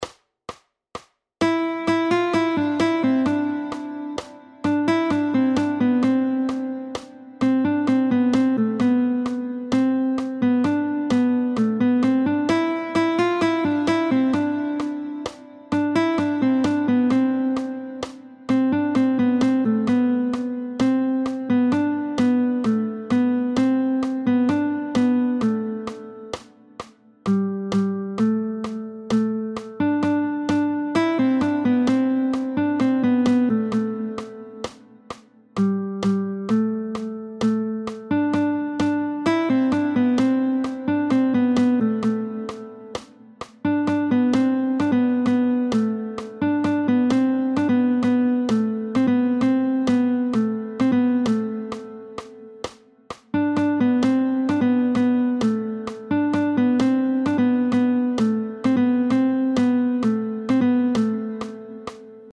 √ برای ساز گیتار | سطح آسان